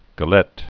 (gə-lĕt)